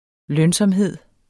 Udtale [ ˈlœnsʌmˌheðˀ ]